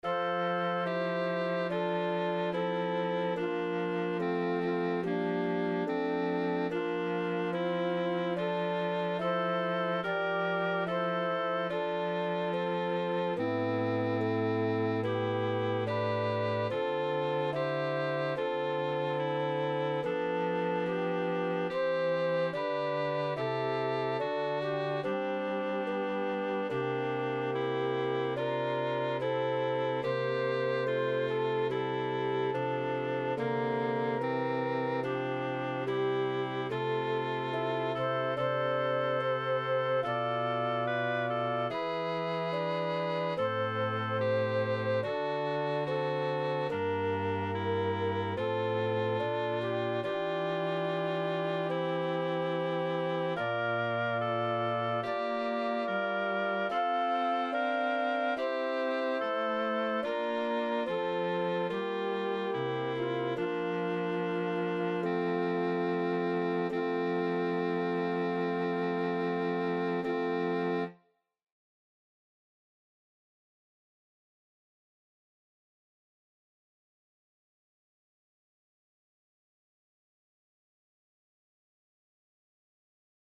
Sopran: FlöteAlt: KlarinetteTenor: FagottBass: Cello
ab Takt 5, ohne Takte 21 - 24, ohne Wiederholung